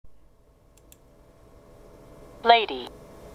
カタカナになると区別がつきませんが、英語では全く発音が違います。
lady（リスニング用音声）